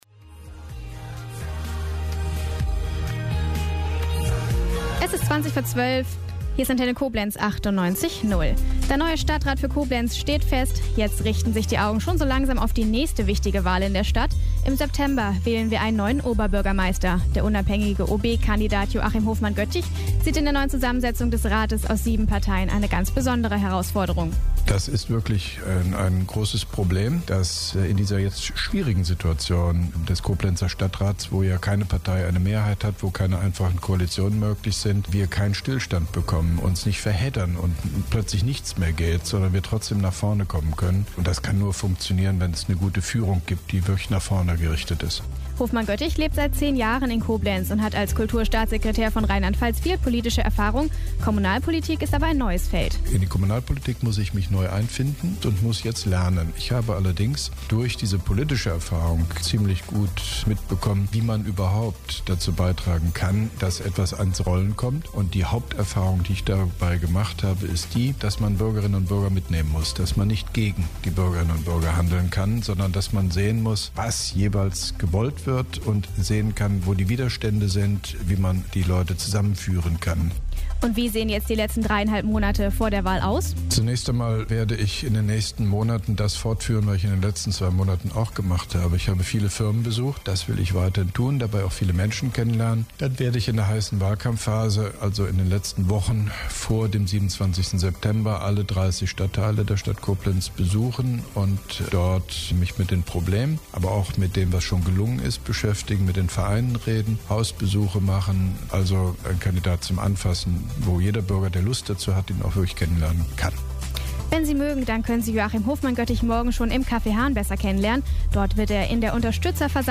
Interviews/Gespräche